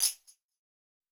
TC PERC 16.wav